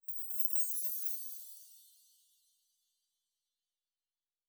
pgs/Assets/Audio/Fantasy Interface Sounds/Magic Chimes 08.wav at master
Magic Chimes 08.wav